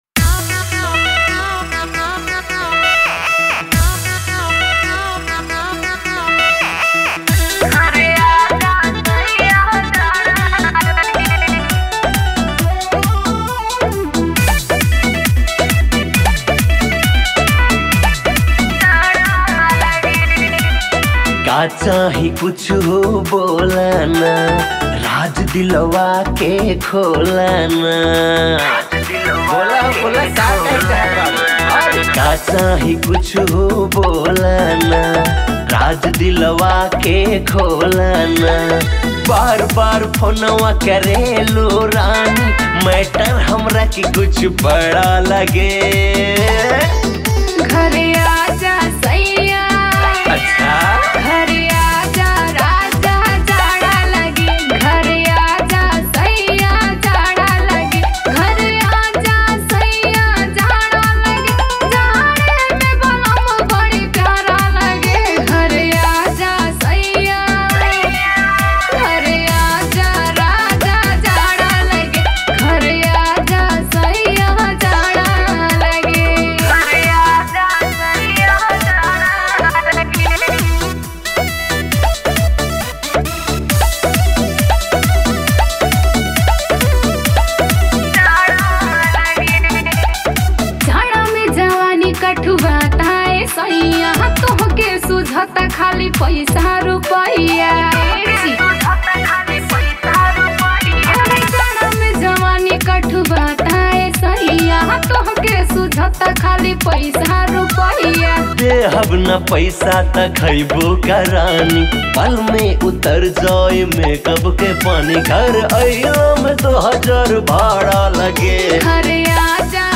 Tharu Mp3 Song